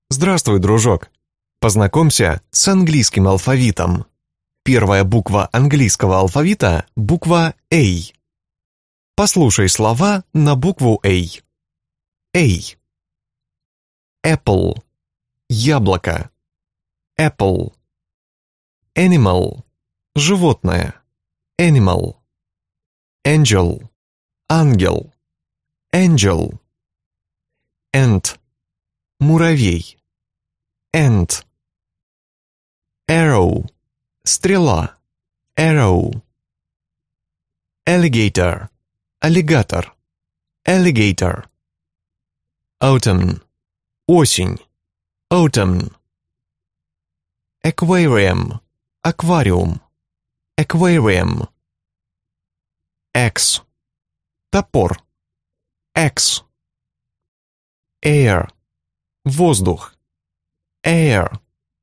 Аудиокнига Английский алфавит для детей | Библиотека аудиокниг